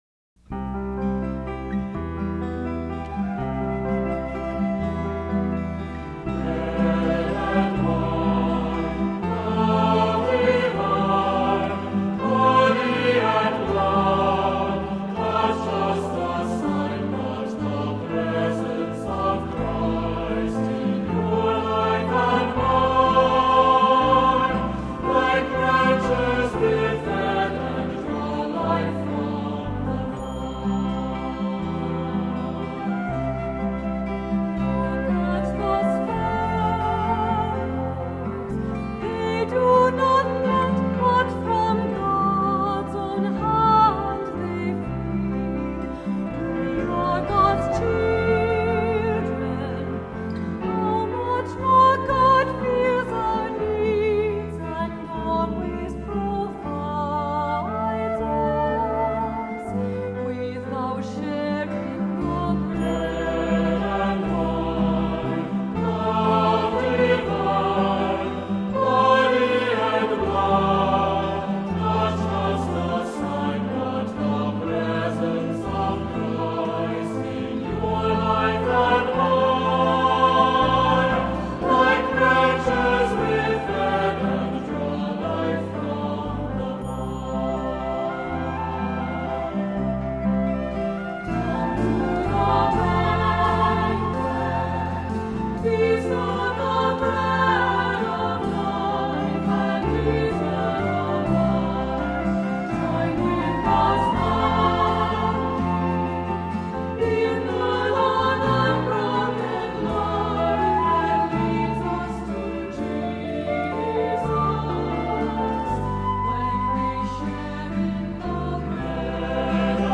Accompaniment:      Piano
Music Category:      Christian
Unison, 2 equal or mixed voices, descant, SATB.ΚΚ